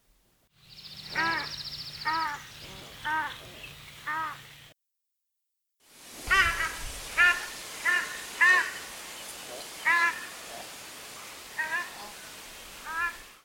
The shelducks’ distinctive calls can be heard by clicking on the audio bar below. This was recorded locally, and comprises mainly the female’s honks, but the male’s deeper response can be heard faintly in the background.
australian-shelduck.mp3